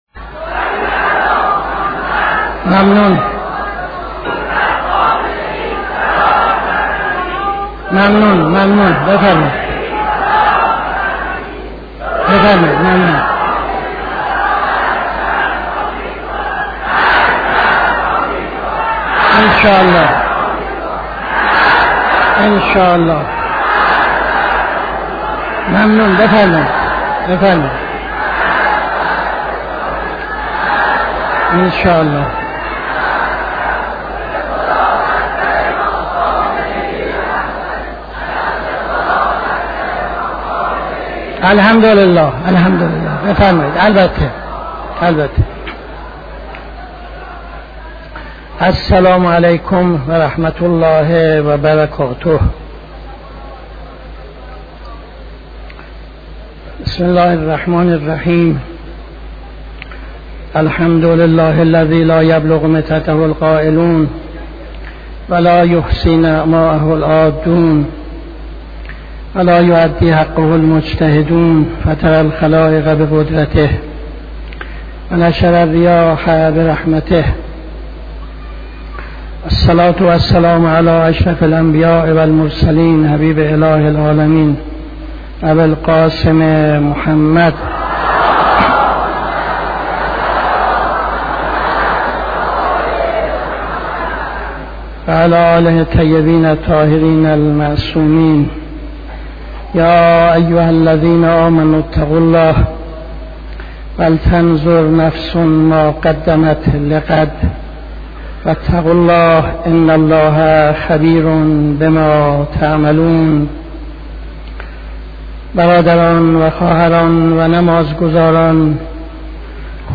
خطبه اول نماز جمعه 24-12-75